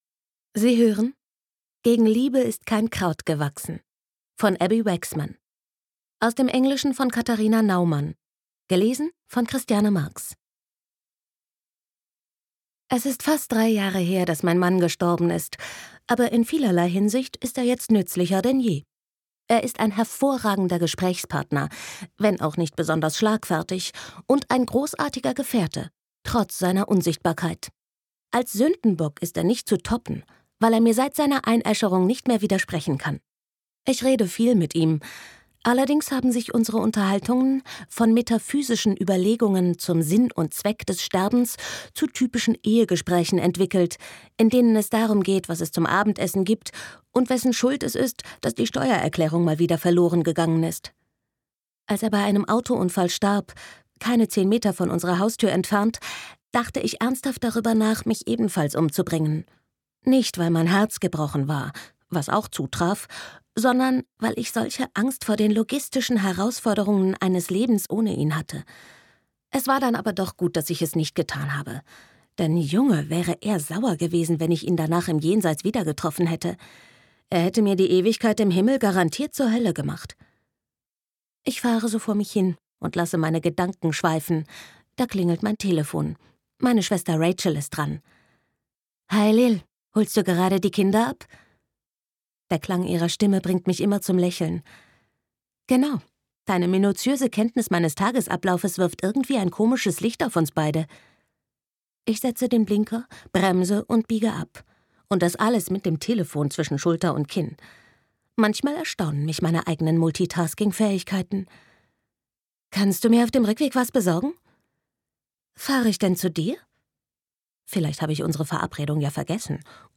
Ob Thriller oder Kinderbuch: Mit ihrer kristallklaren, ausdrucksstarken Stimme nimmt sie die Hörer mit in die verschiedensten Roman-Landschaften.